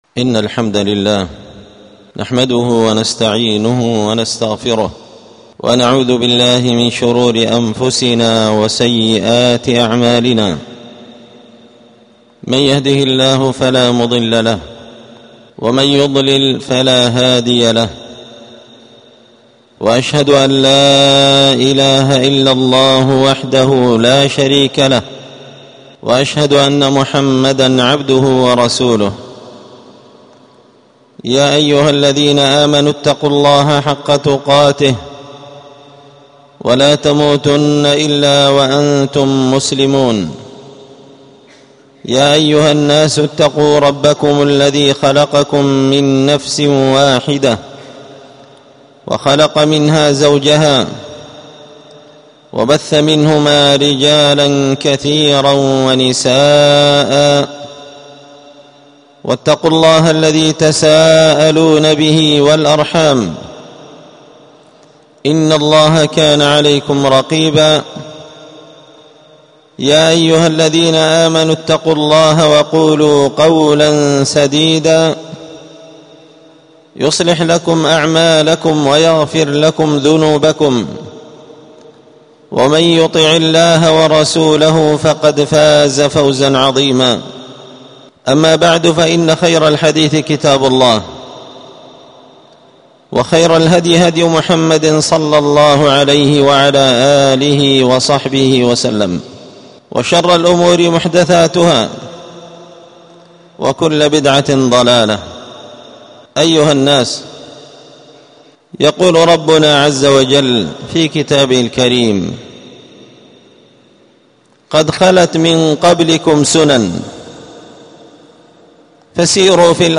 ألقيت هذه الخطبة بدار الحديث السلفية بمسجد الفرقان